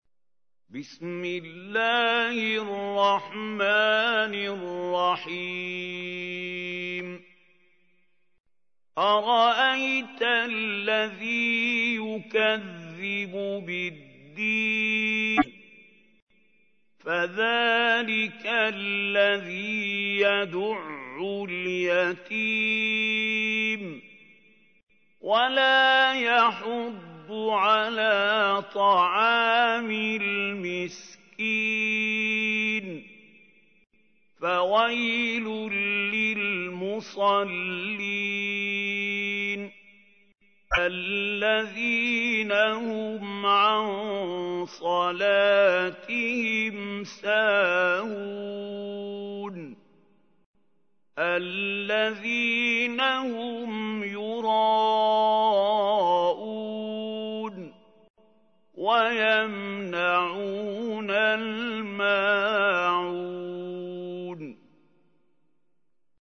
تحميل : 107. سورة الماعون / القارئ محمود خليل الحصري / القرآن الكريم / موقع يا حسين